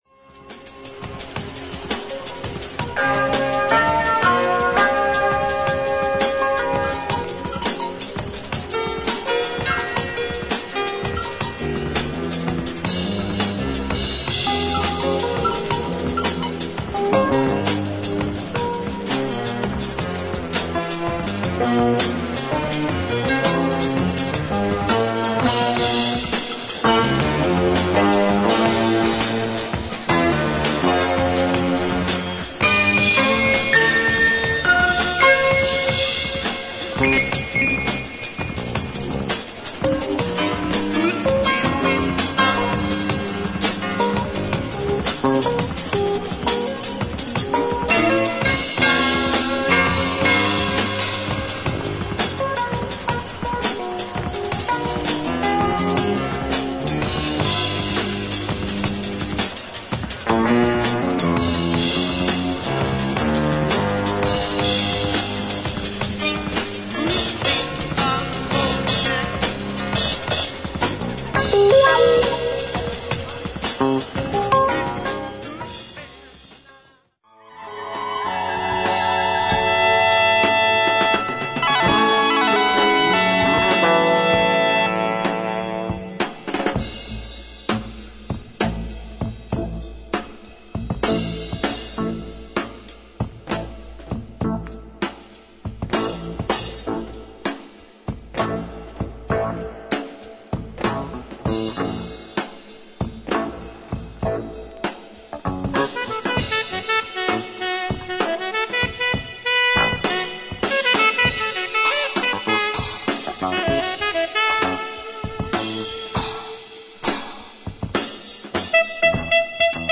スピリチュアルかつスペイシーな
民族楽器を多用し、エスノな桃源郷フュージョンを展開する